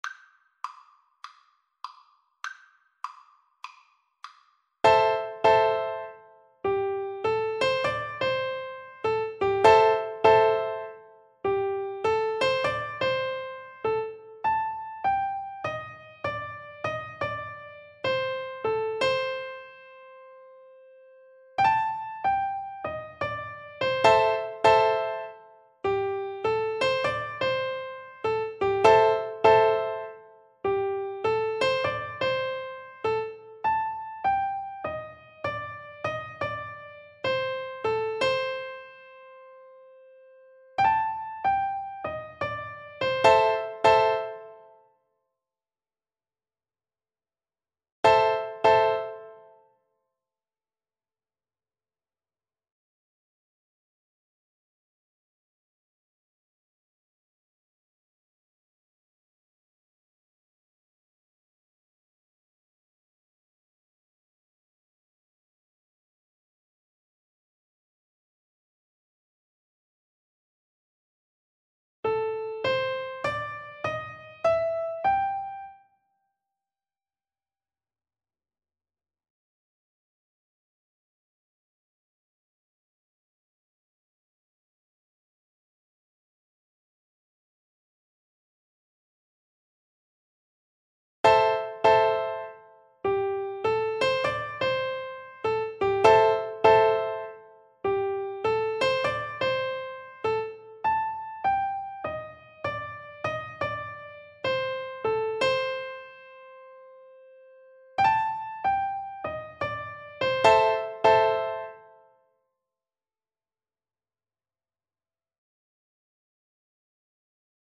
Swung
Piano Duet  (View more Easy Piano Duet Music)
Jazz (View more Jazz Piano Duet Music)